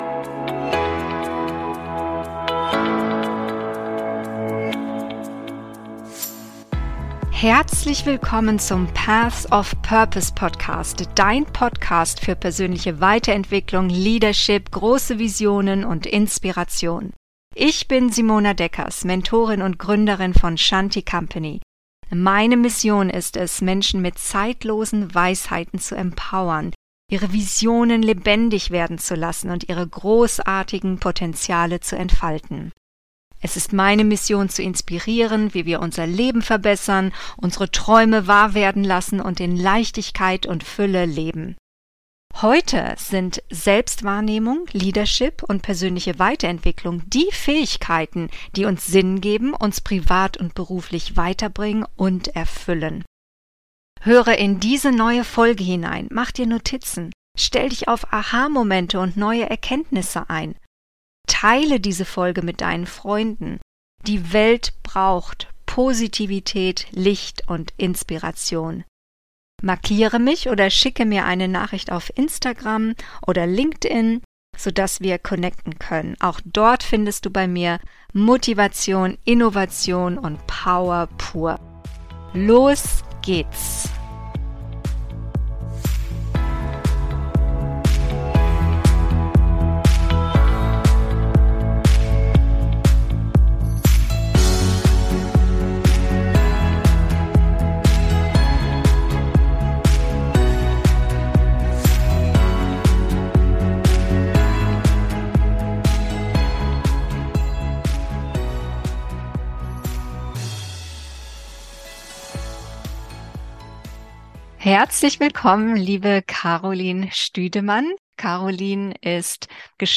Wasser für alle, alle für Wasser! - Interview